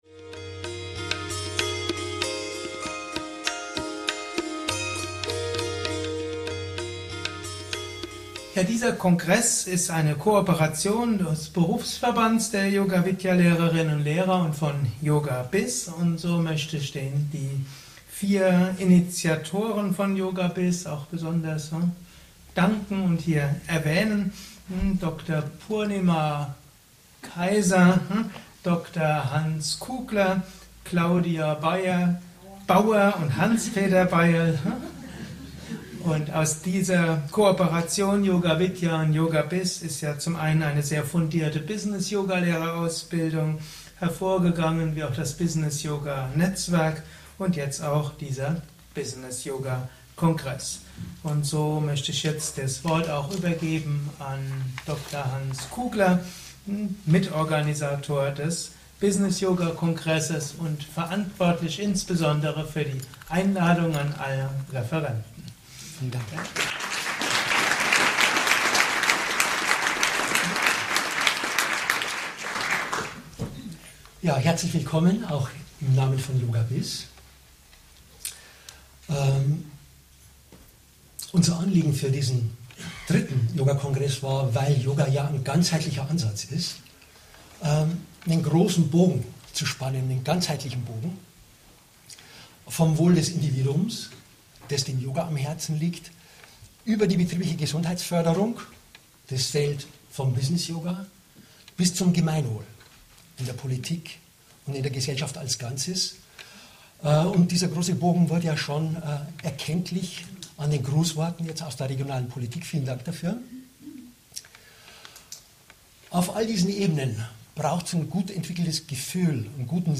Letzter Teil der Eröffnungsrede des 3. Business Yoga